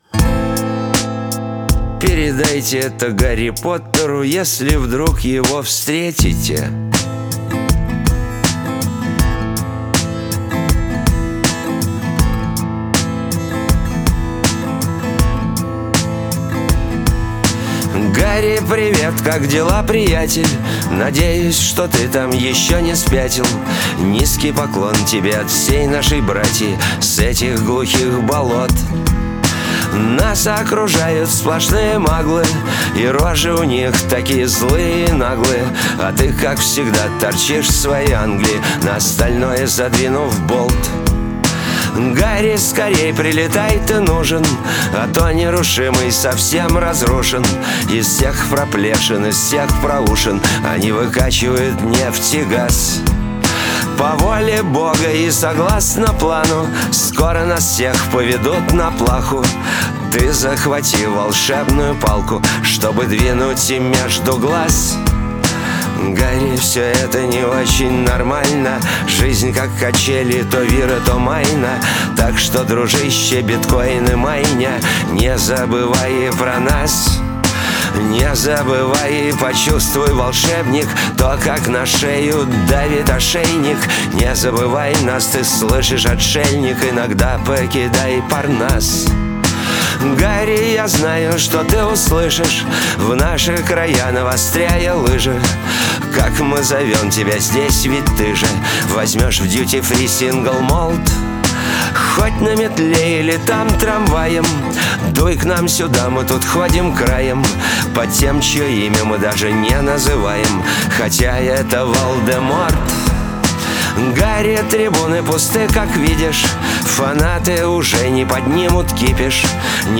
Альтернативный рок Инди-рок Электроник-рок